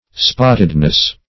Spottedness \Spot"ted*ness\, n. State or quality of being spotted.
spottedness.mp3